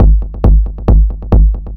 Kick 136-BPM.wav